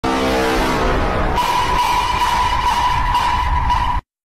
Among_us_bass_boosted_meme.2.wav